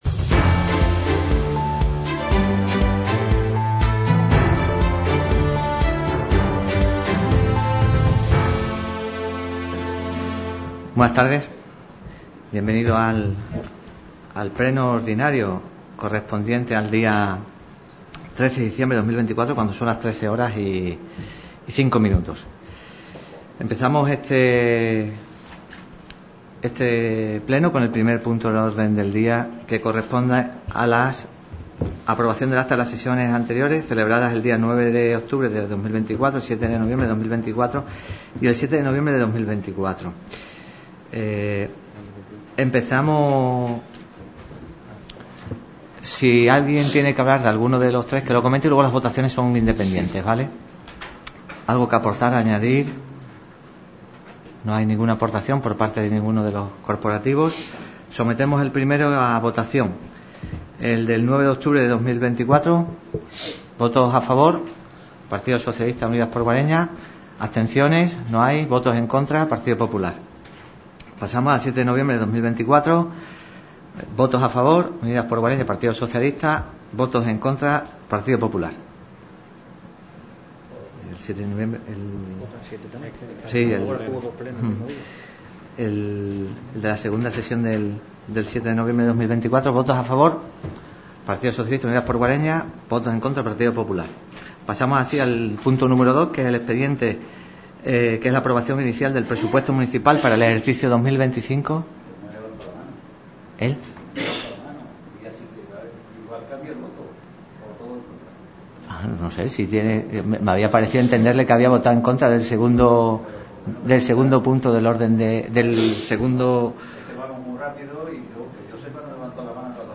Sesión ORDINARIA de Pleno, 13 de Diciembre de 2024 - radio Guareña